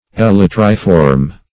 Meaning of elytriform. elytriform synonyms, pronunciation, spelling and more from Free Dictionary.
Search Result for " elytriform" : The Collaborative International Dictionary of English v.0.48: Elytriform \E*lyt"ri*form\, a. [Elytrum + -form.]